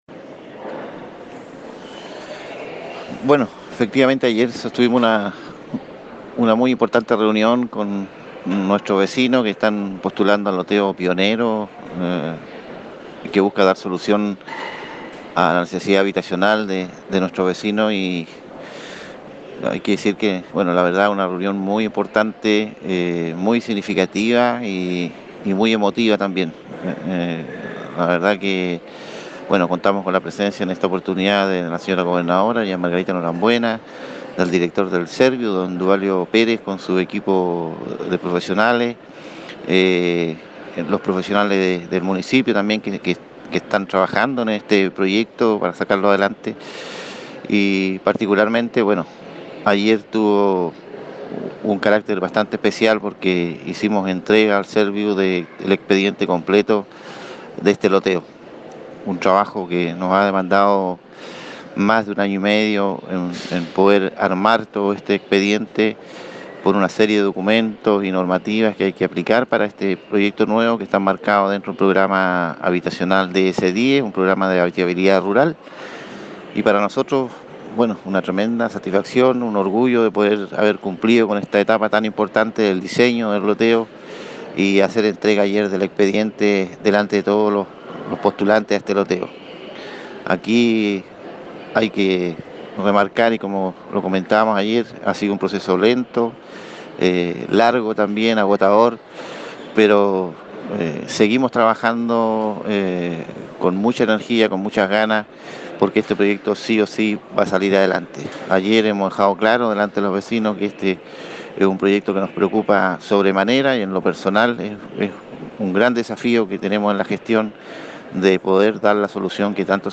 Entrevista-alcalde-sobre-proyecto-habitacional.mp3